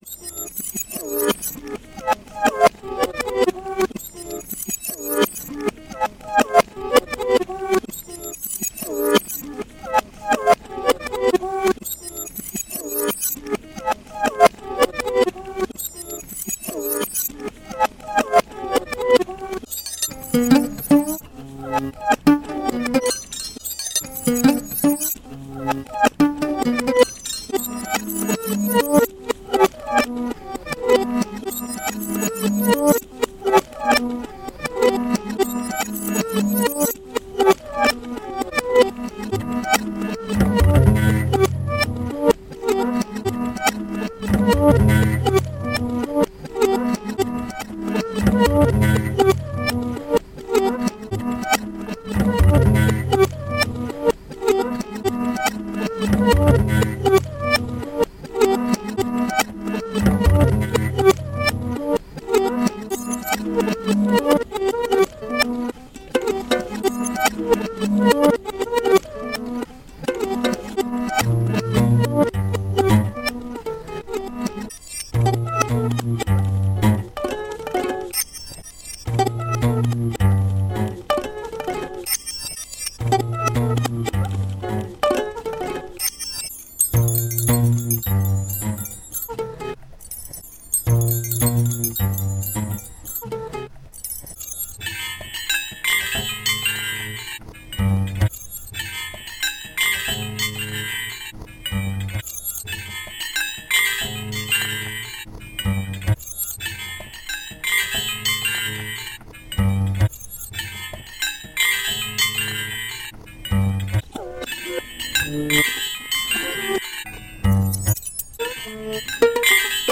padanisa.py([FretlessUkulele,GlockenBells].pa(3min))